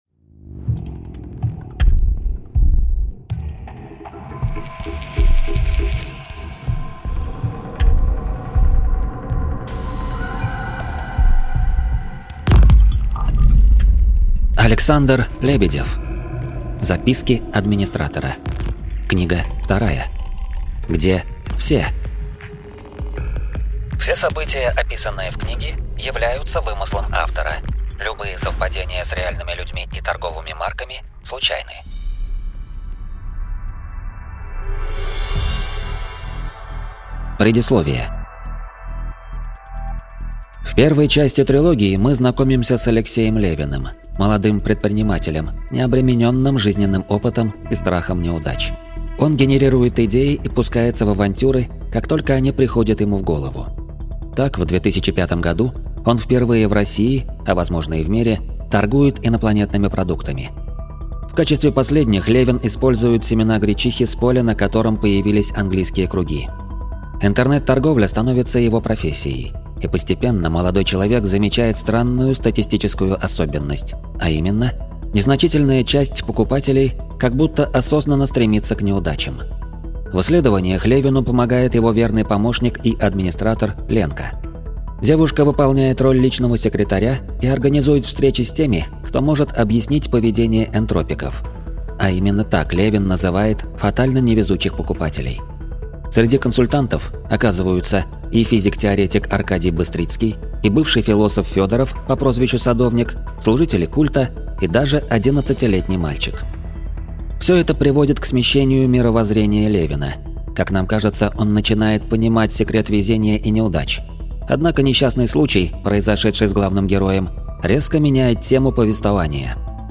Аудиокнига Записки администратора – 2. Где все?